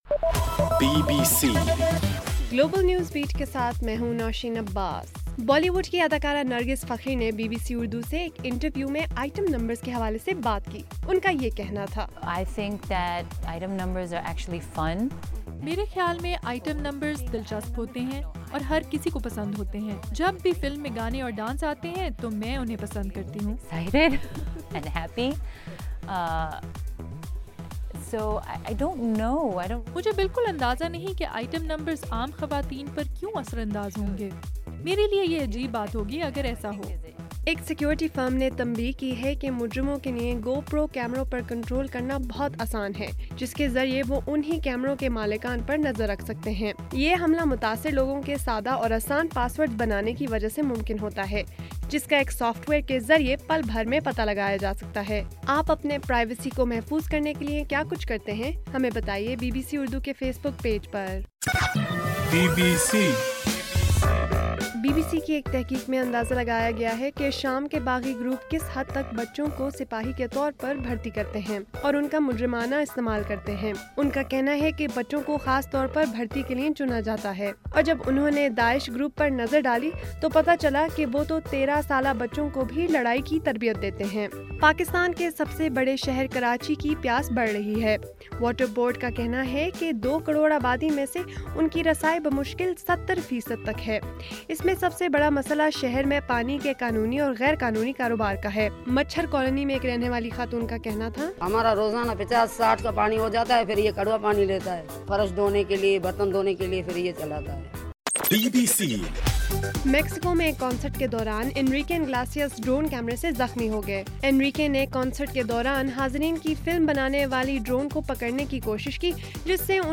جون 1: رات 10 بجے کا گلوبل نیوز بیٹ بُلیٹن